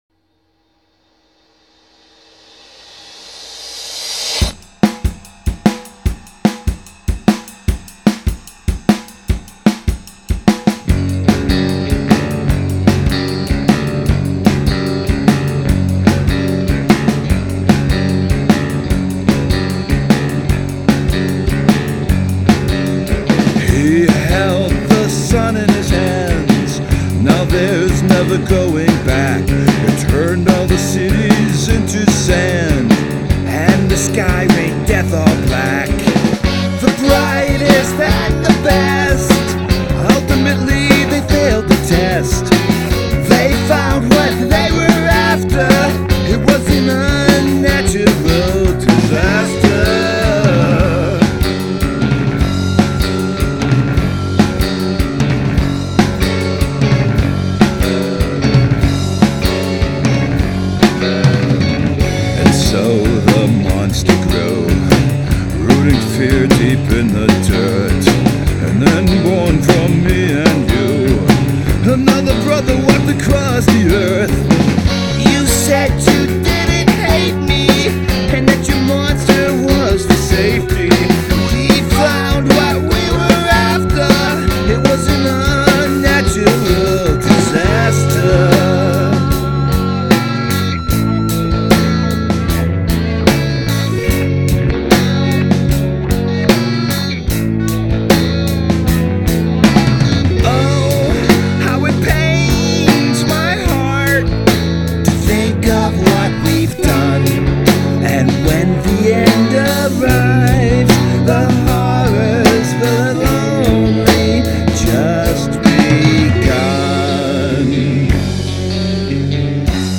Must include prominent use of backwards recording